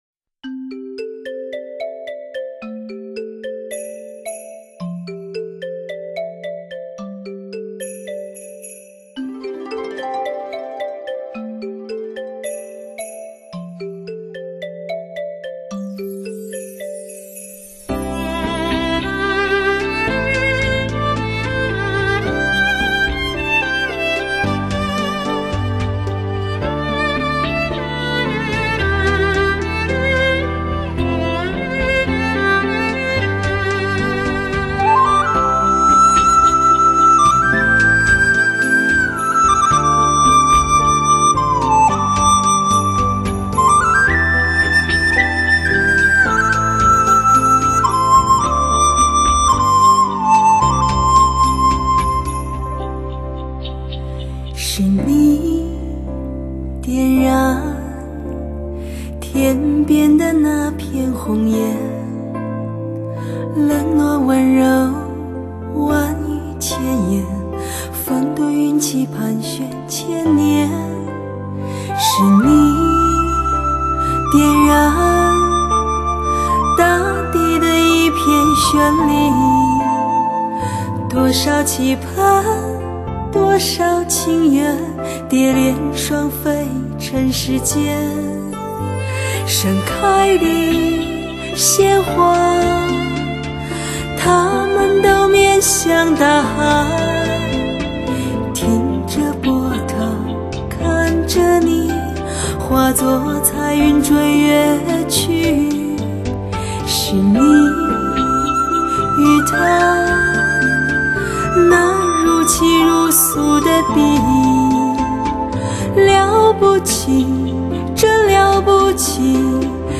专辑中一首首唯美悠婉的老歌，感伤悲泣，回荡在人们的记忆中，经过岁月的洗礼，更加触动心弦！